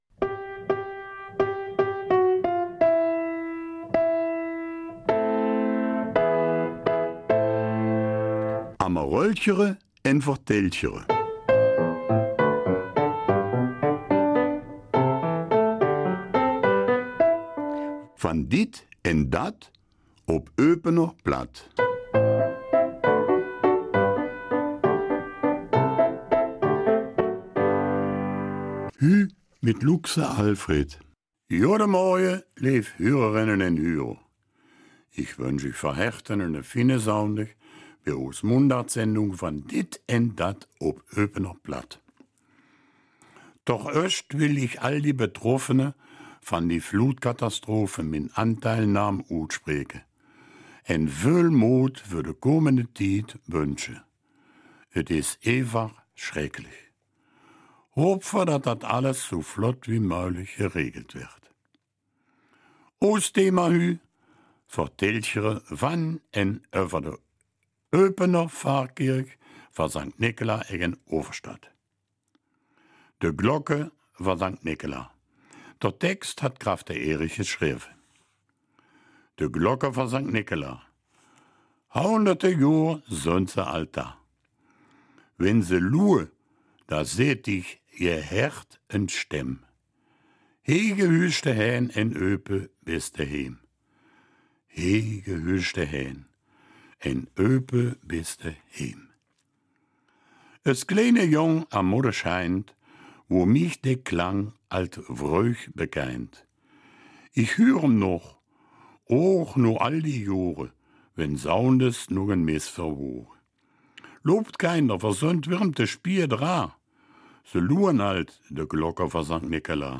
Eupener Mundart: St. Nikolaus Pfarrkirche